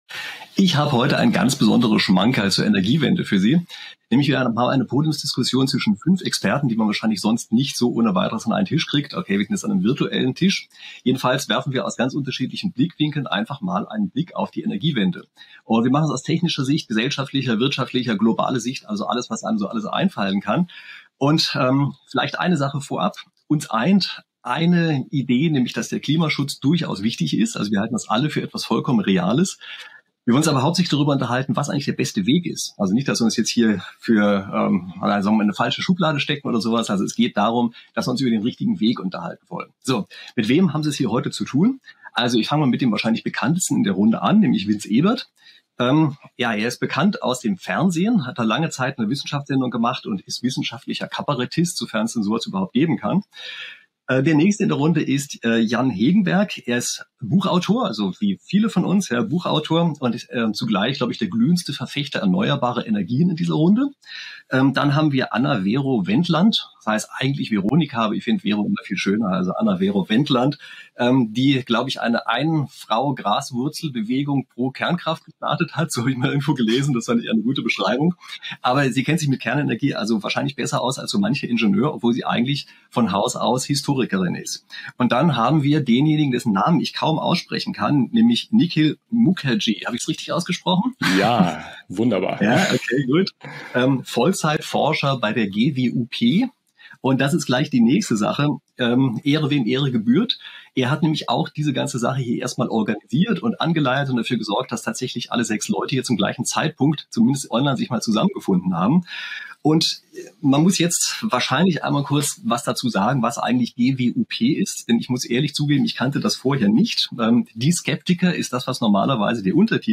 Die Diskussion fand am 03.05.23 in Kooperation mit der GWUP e.V. statt.